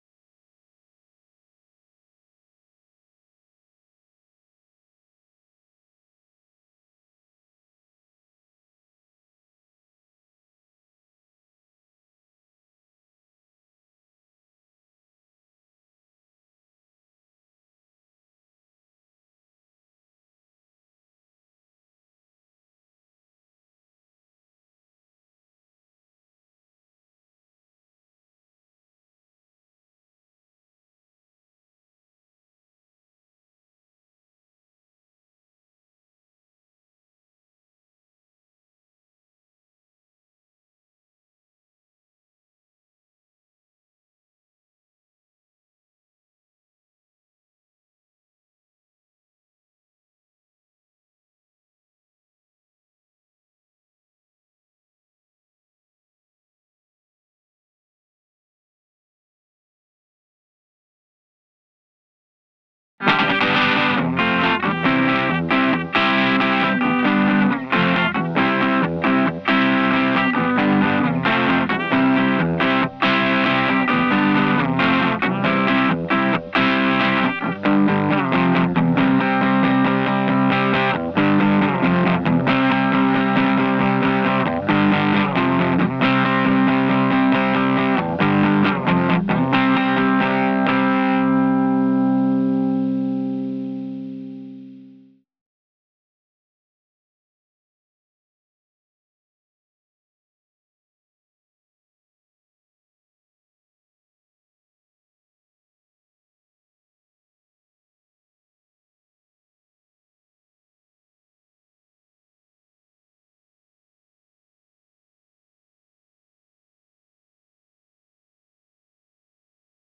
Faith_Crunch Guitar 2 render 001.wav